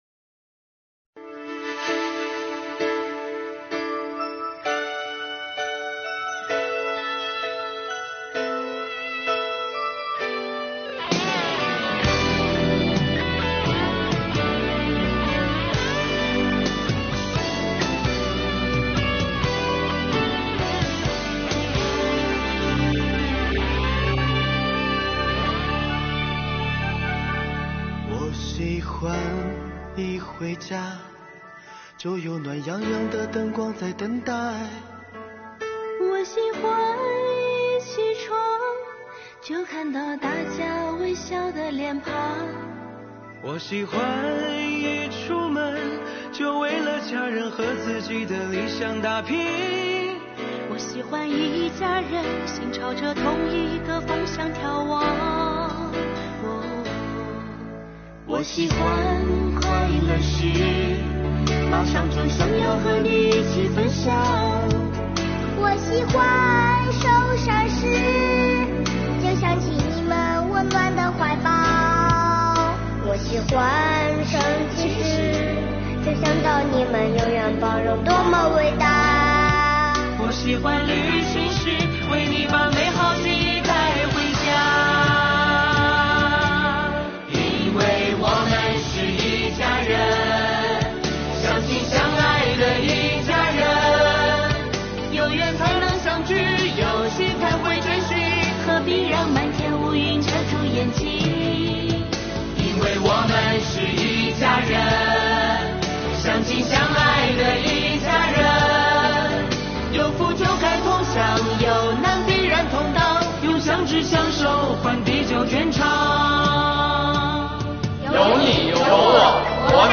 税务干部们用歌声传递祝福，用动作表达情感，在新的一年祝愿伟大祖国繁荣昌盛。